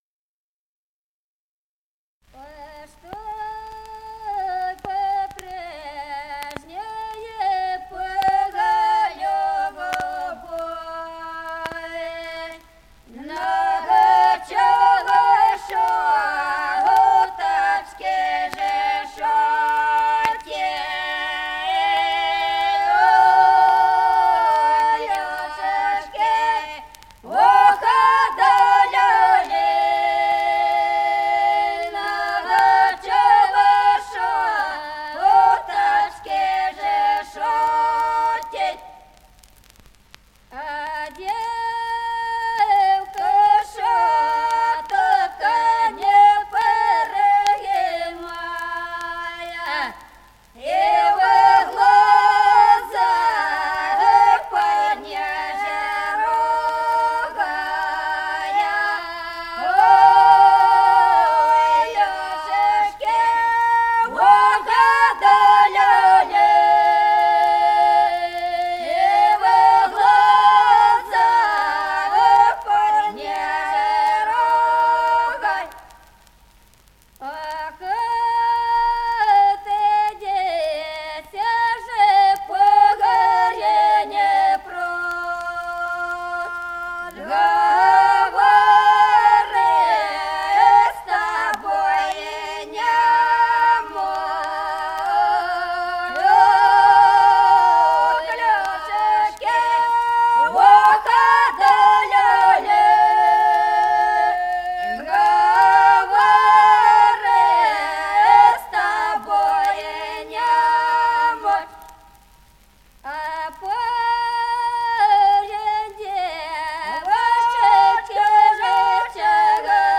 Песни села Остроглядово. Что по прежней по любови.